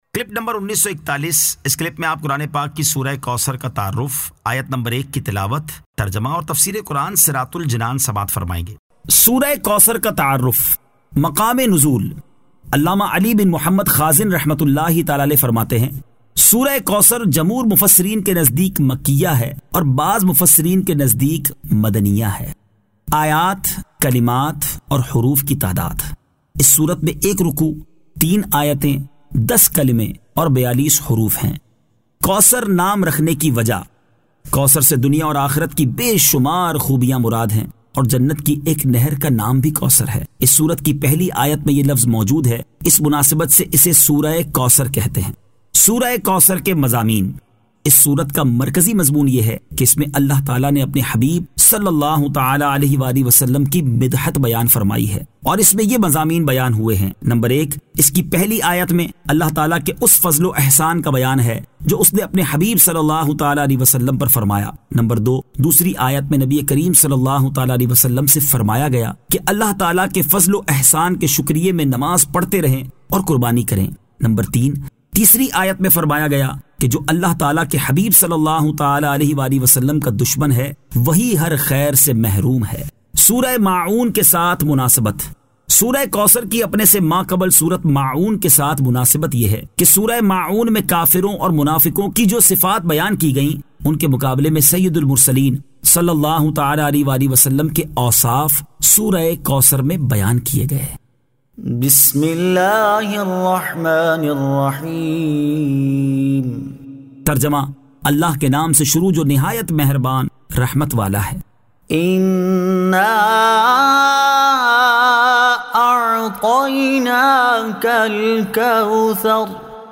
Surah Al-Kawthar 01 To 01 Tilawat , Tarjama , Tafseer
2025 MP3 MP4 MP4 Share سُوَّرۃُ الْکَوْثَرْ آیت 01 تا 01 تلاوت ، ترجمہ ، تفسیر ۔